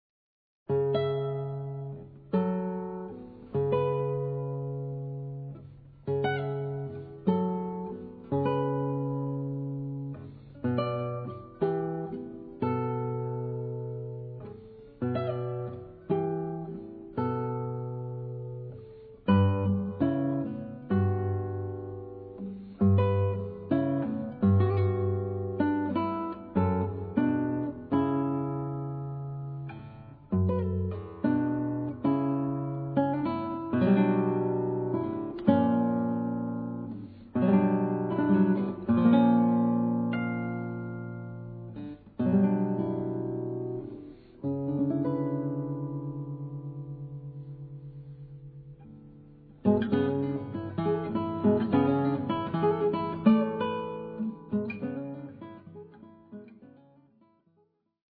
Classical
Solo guitar